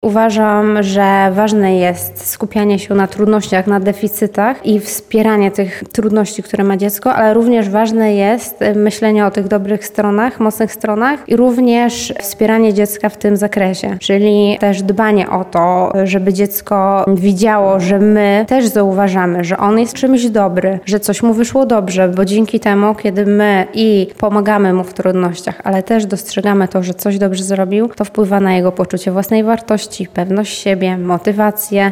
O autyzmie rozmawiali uczestnicy konferencji „Barwy Neuroróżnorodności” w Lublinie. Wśród poruszonych tematów była kwestia odkrywania mocnych stron u dzieci w spektrum.